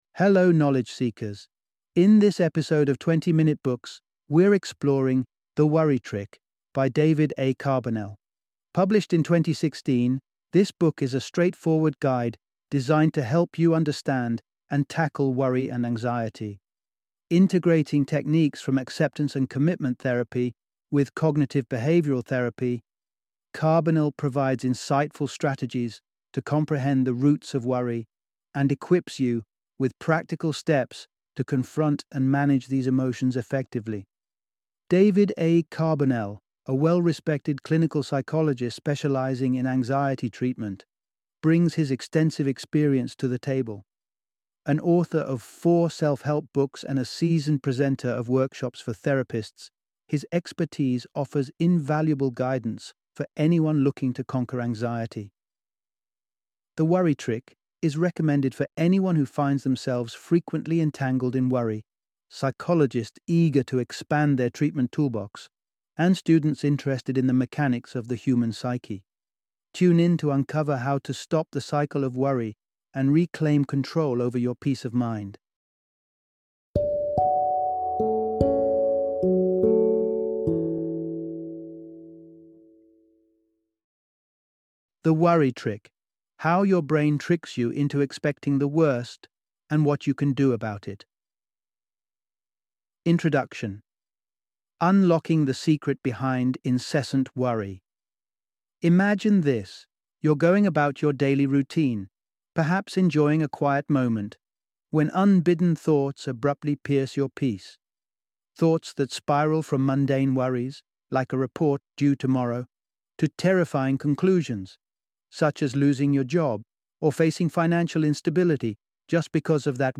The Worry Trick - Audiobook Summary